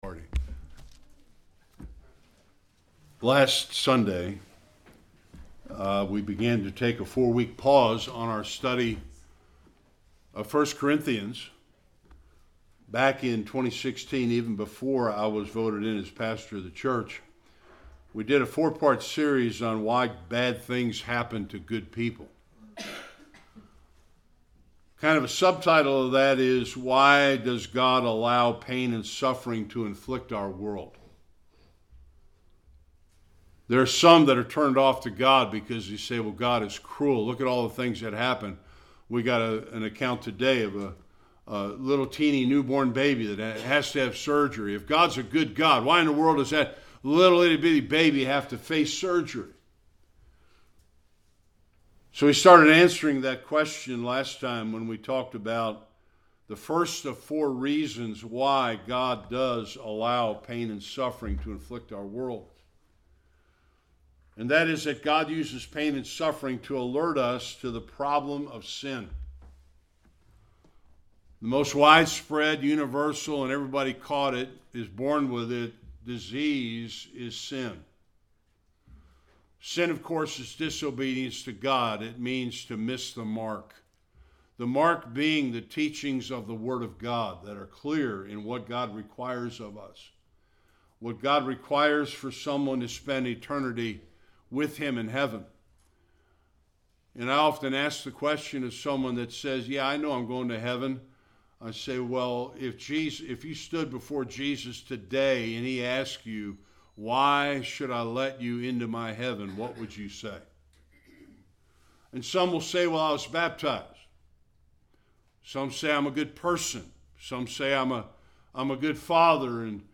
Various Passages Service Type: Sunday Worship God sometimes allows suffering in our lives to direct us to Him.